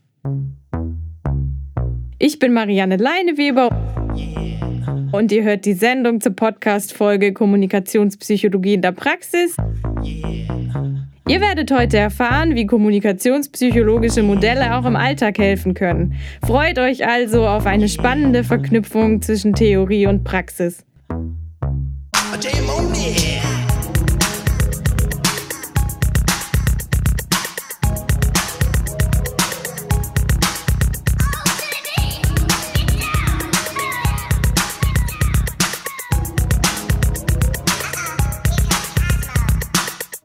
464_Teaser.mp3